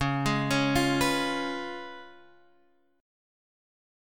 Db7 chord